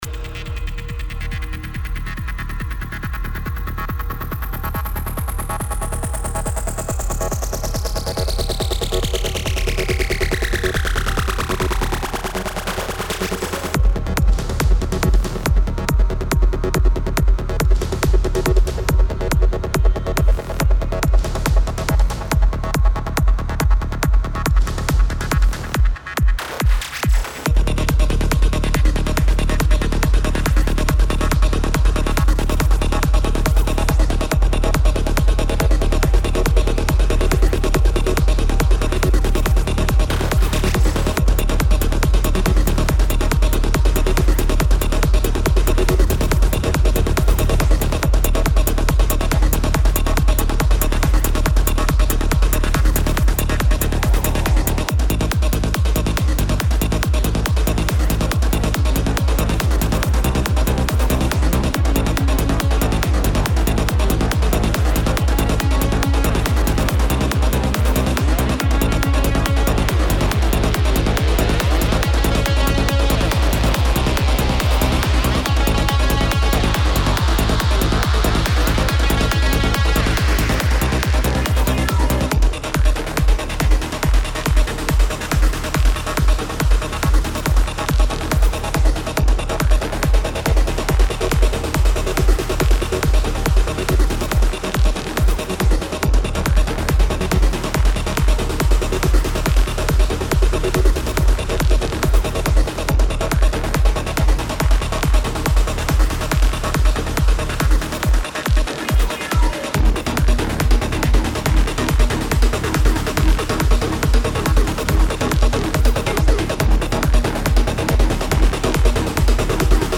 is an EDM based original release and is loopable.
Tempo 140BPM (Allegro)
Genre EDM
Type Instrumental
Mood Energetic
Render Loudness -6 LUFS